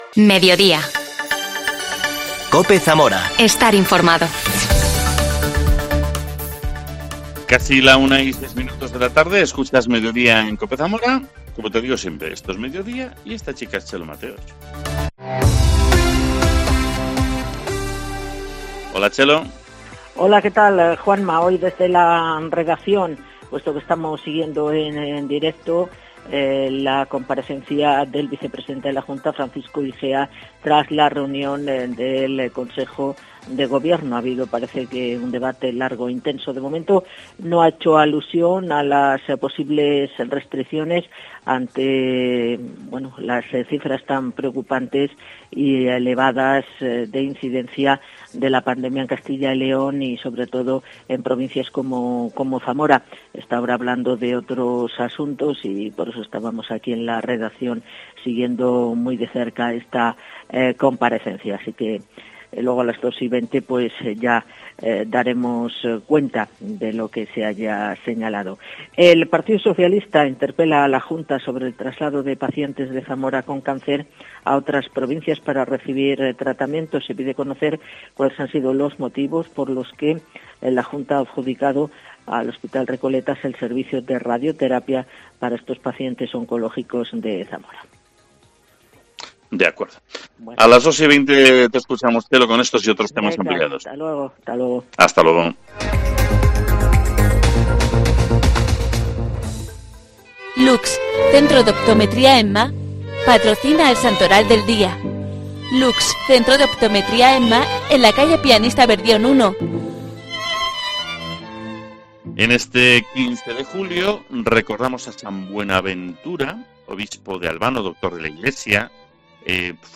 AUDIO: Hoy se ha descubierto la placa ‘Jardines Escultor Baltasar Lobo’. Hablamos con el concejal de Comercio y Turismo, Christoph Strieder.